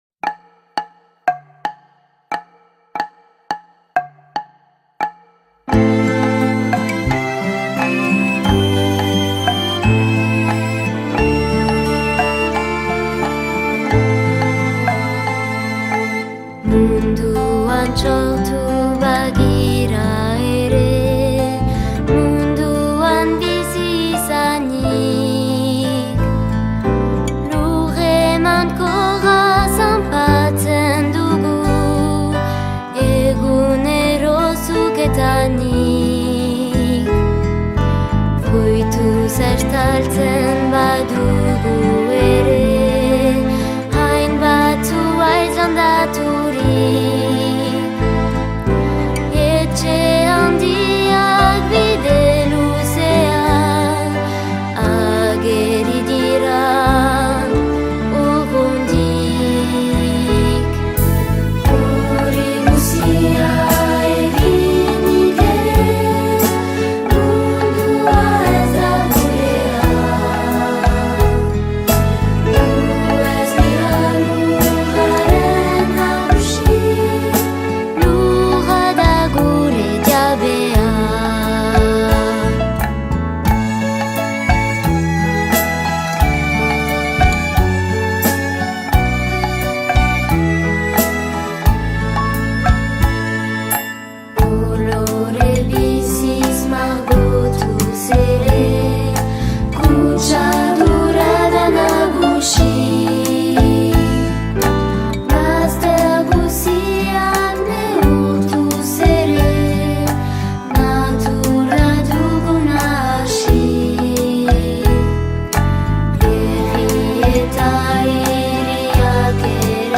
Kantua